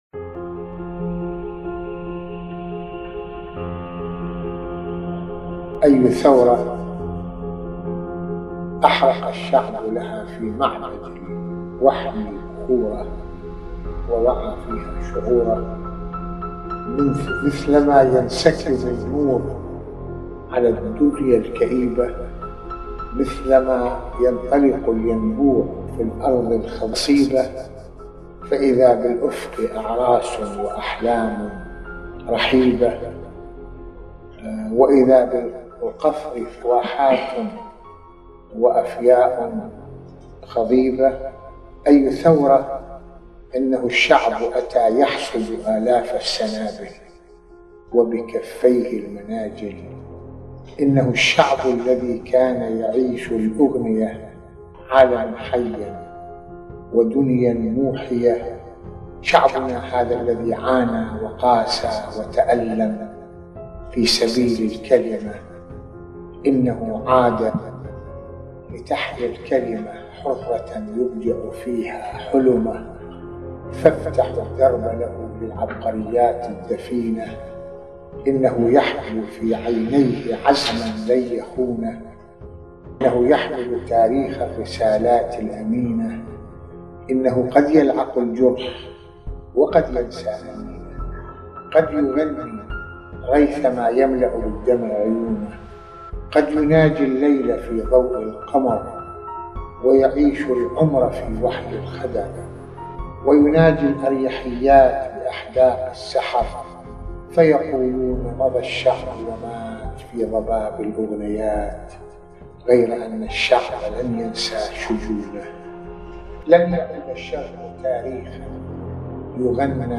شعر